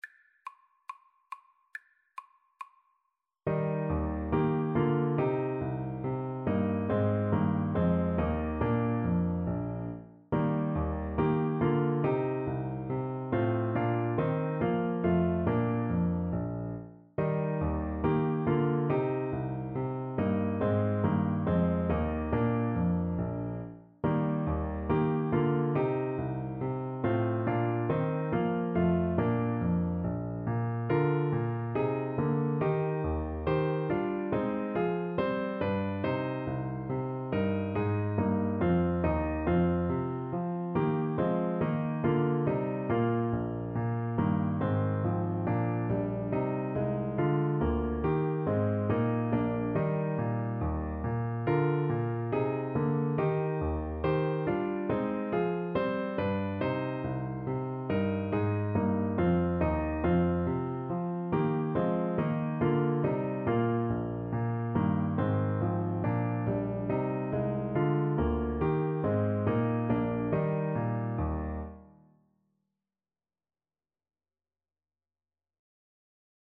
Eb major (Sounding Pitch) (View more Eb major Music for Trombone )
Classical (View more Classical Trombone Music)